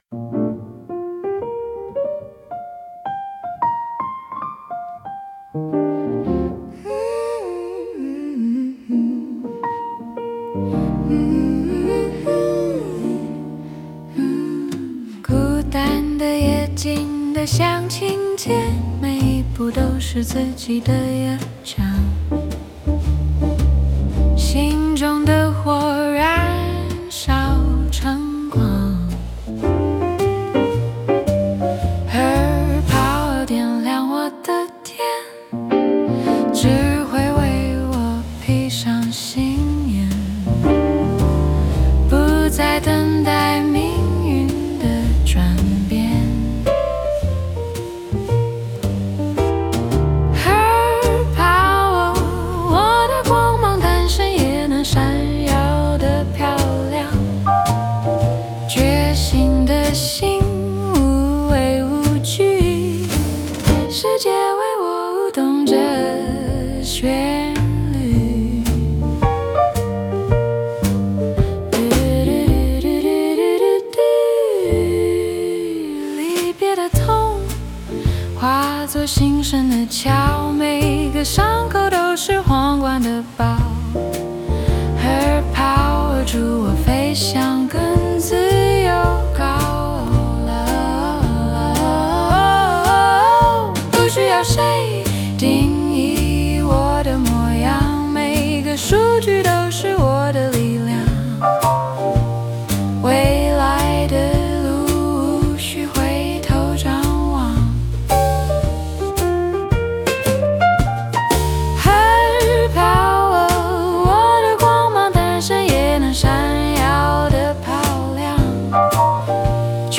爵士抒情
不插電爵士和聲版・深夜鋼琴吧演奏語言
medium-slow｜微弱 swing、自由 phrasing
女聲主唱,直立鋼琴
當旋律走進爵士和聲裡，情緒不再張揚，卻更靠近。